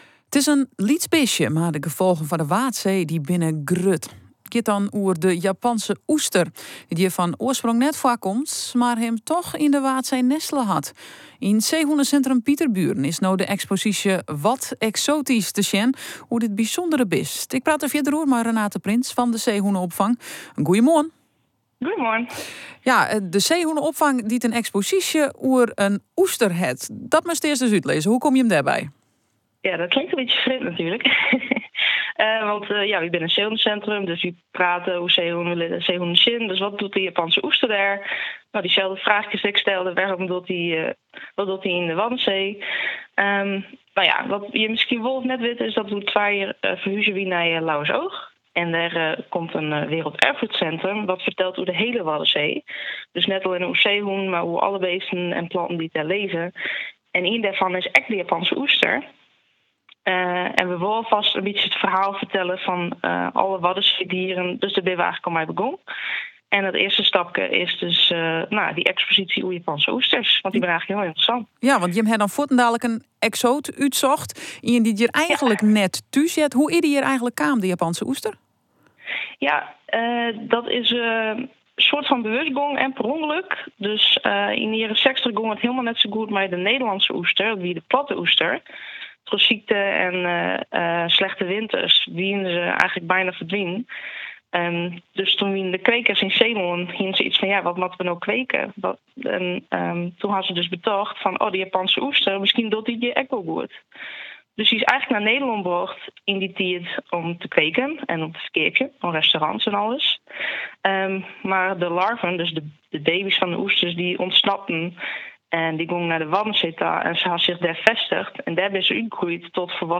Japanse-Oester-yn-Waadsee-Live.wav